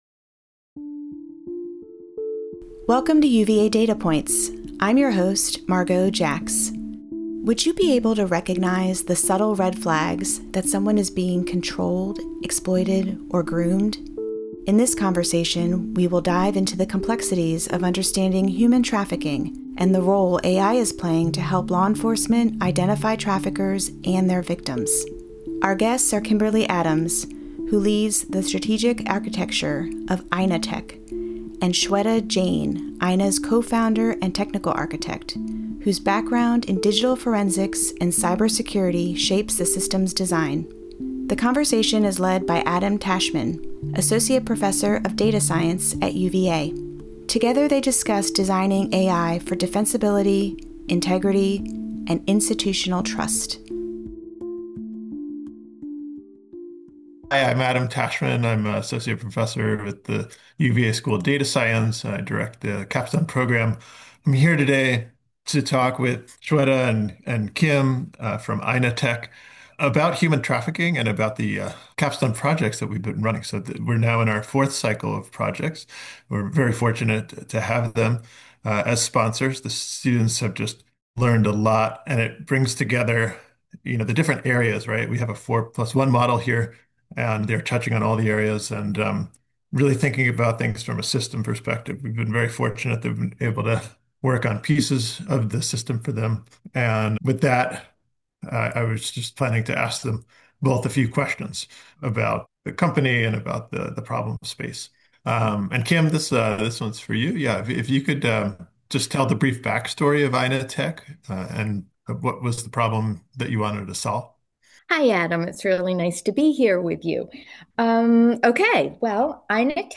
In this conversation, we will dive into the complexities of understanding human trafficking and the role AI is playing to help law enforcement identify traffickers and their victims.